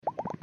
bubbleSFX.MP3